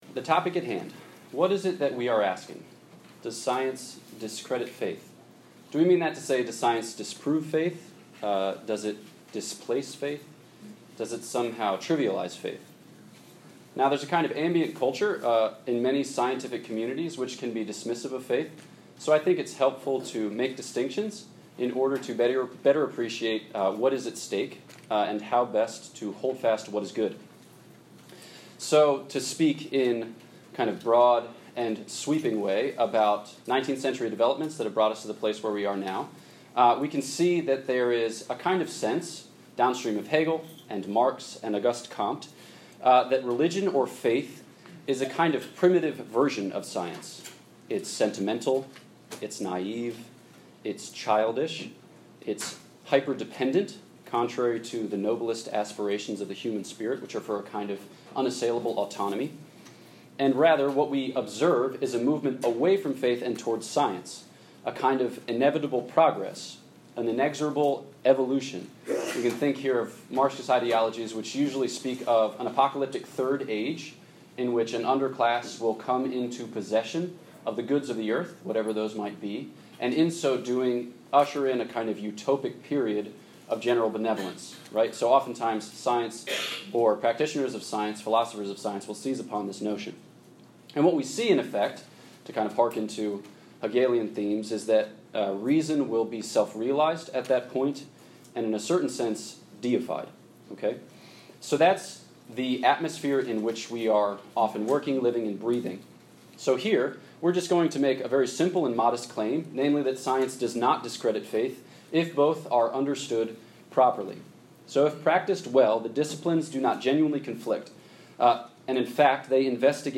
This lecture was given on September 17, 2019 at John Hopkins University.